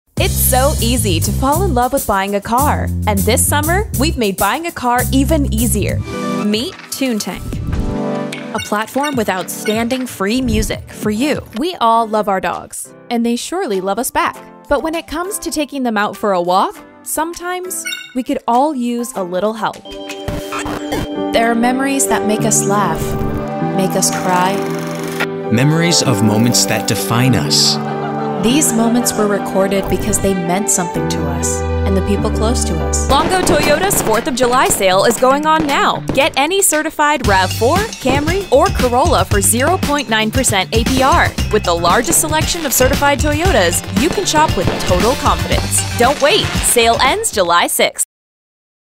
Female Voice Over, Dan Wachs Talent Agency.
Sincere, Animated, Real, Youthful.
Commercial